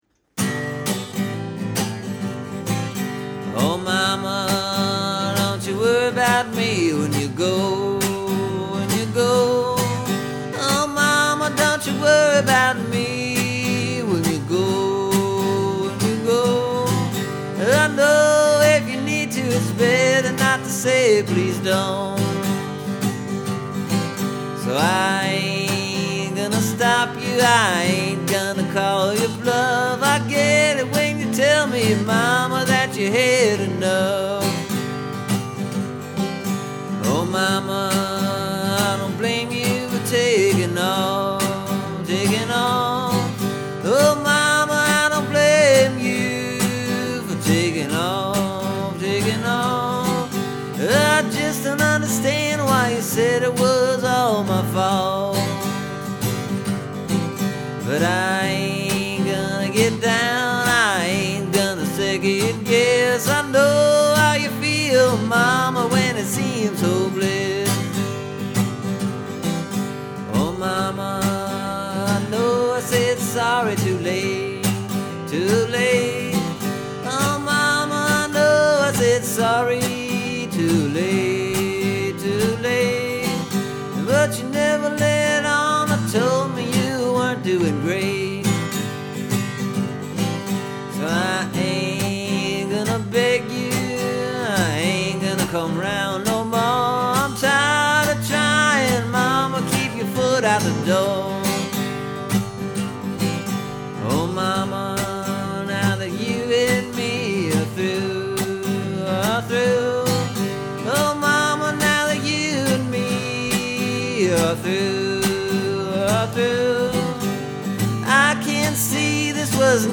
There’s something about the rhythm of the music and the way the words match up to it that’s both interesting to me and very hard to sing.